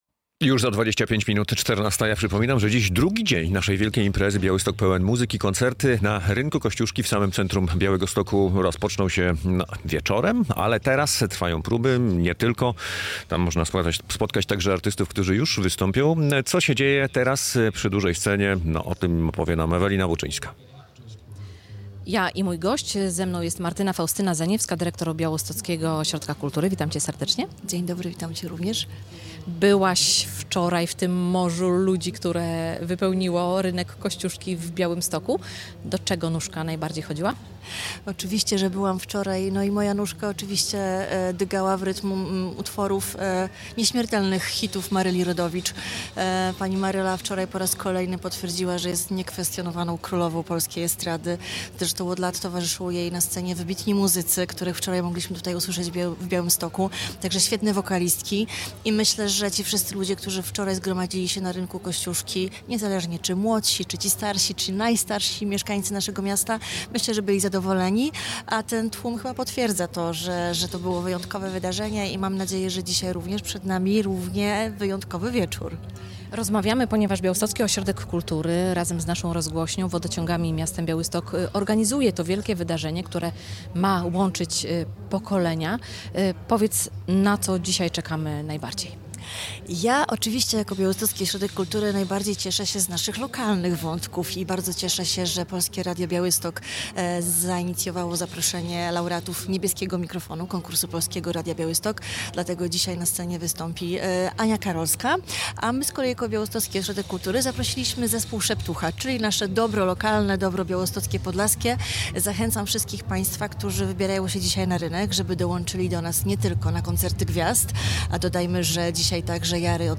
Jest też nasze plenerowe studio - nadajemy z Rynku Kościuszki od 12:00 do 17:00.